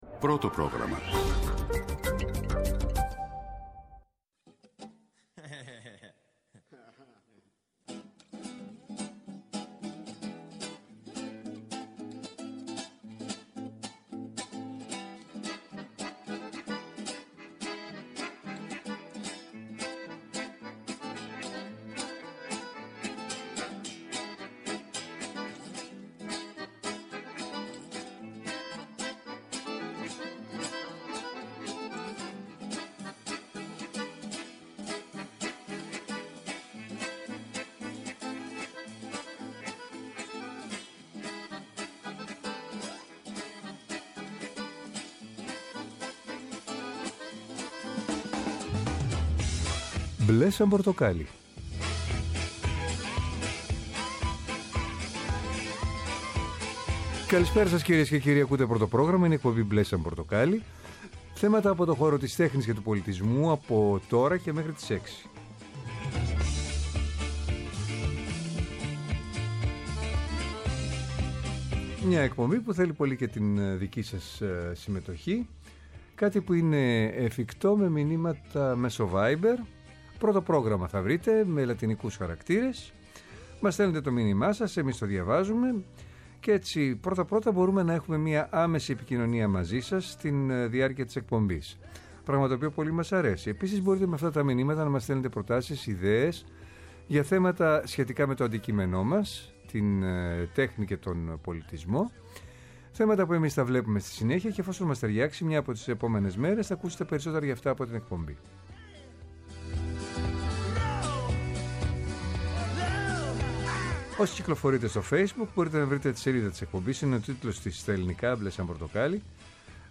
“Μπλε σαν πορτοκάλι”. Θέατρο, κινηματογράφος, μουσική, χορός, εικαστικά, βιβλίο, κόμικς, αρχαιολογία, φιλοσοφία, αισθητική και ό,τι άλλο μπορεί να είναι τέχνη και πολιτισμός, καθημερινά από Δευτέρα έως Παρασκευή 5-6 το απόγευμα από το Πρώτο Πρόγραμμα. Μια εκπομπή με εκλεκτούς καλεσμένους, άποψη και επαφή με την επικαιρότητα.